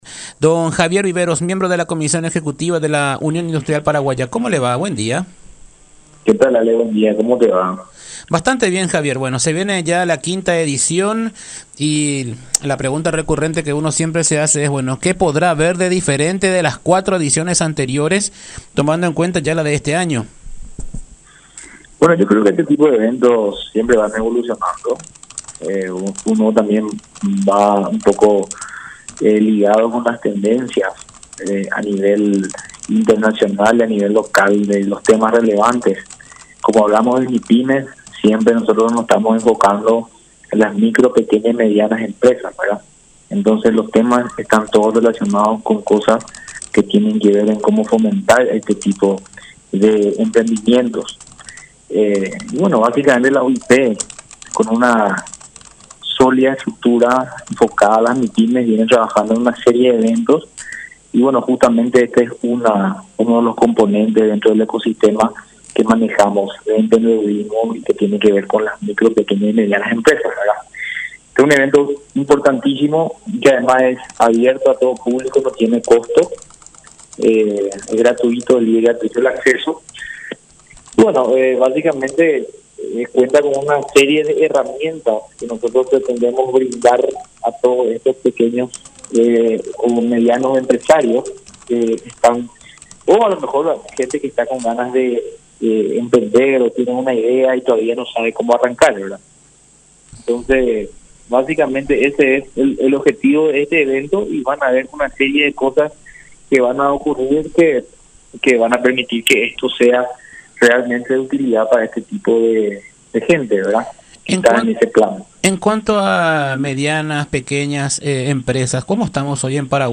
en charla con La Unión.